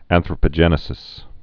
(ănthrə-pə-jĕnĭ-sĭs)